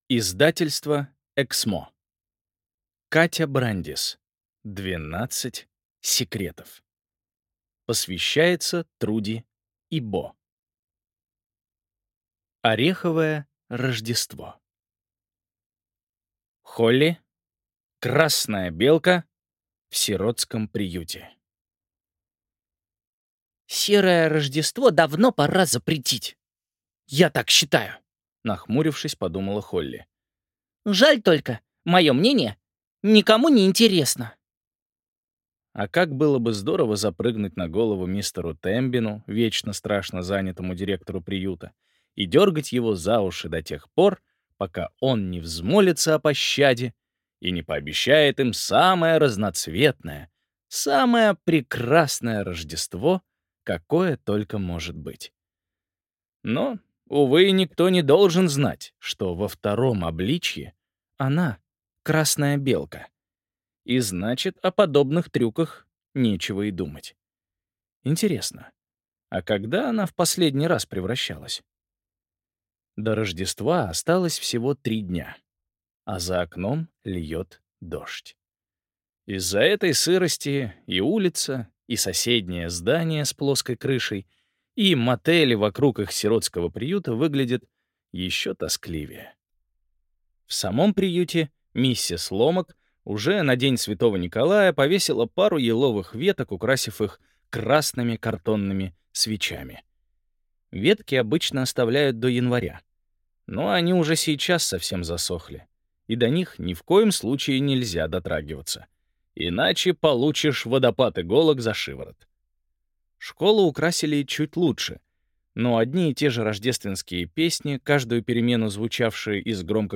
Аудиокнига Двенадцать секретов | Библиотека аудиокниг